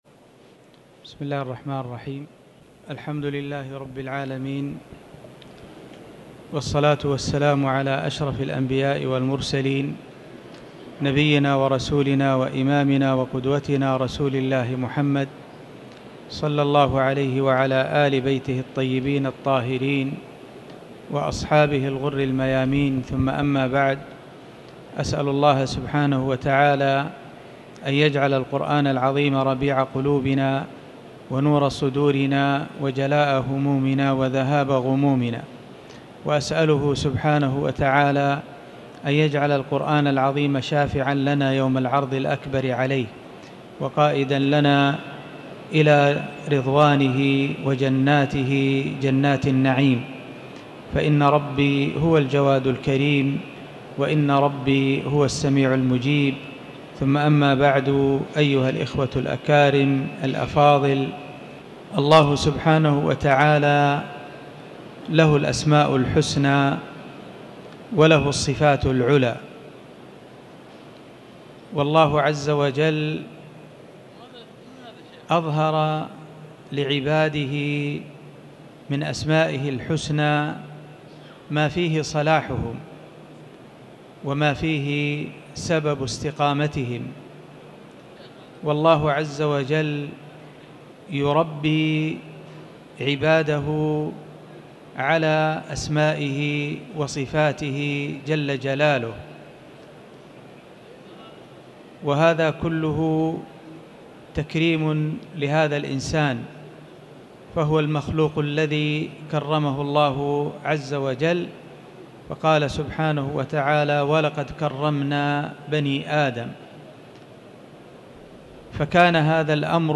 تاريخ النشر ٧ محرم ١٤٤٠ هـ المكان: المسجد الحرام الشيخ